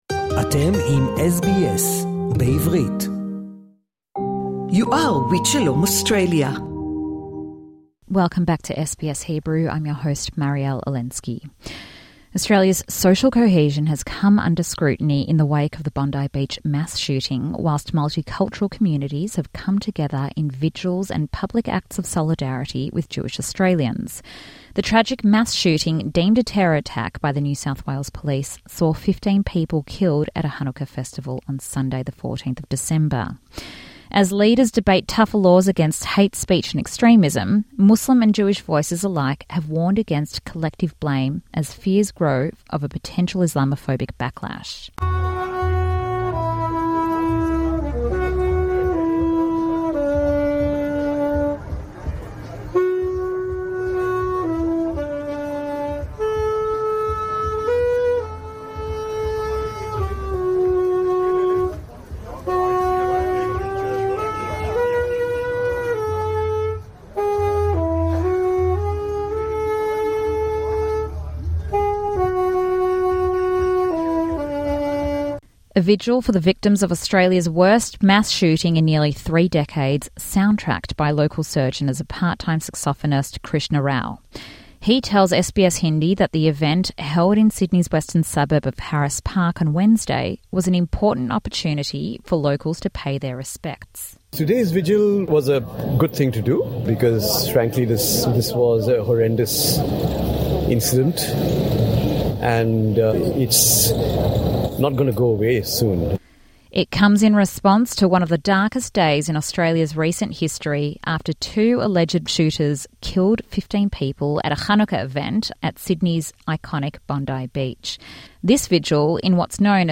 SBS Hebrew hears from Australia’s multicultural community, where a plethora of vigils were held across Sydney to honour the fallen. We hear from Arabic, Thai and Turkish speakers, amongst others.